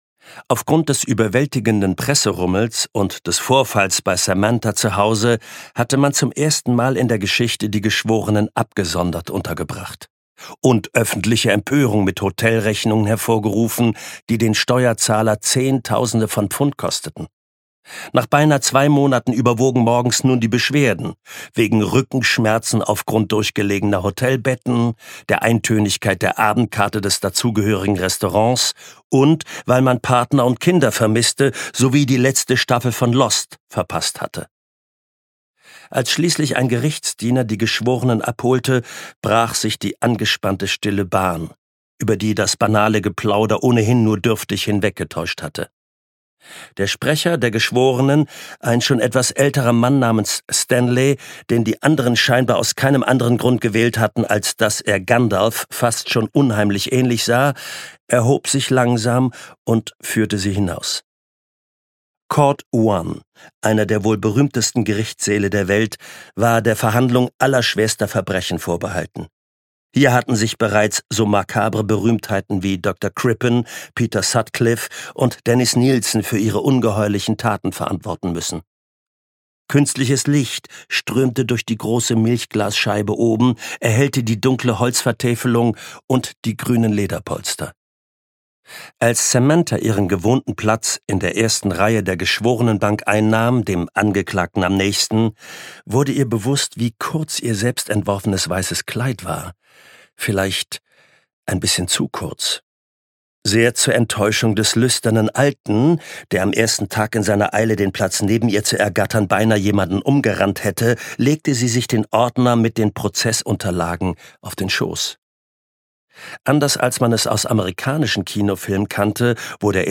Ragdoll - Dein letzter Tag (Ein New-Scotland-Yard-Thriller 1) - Daniel Cole - Hörbuch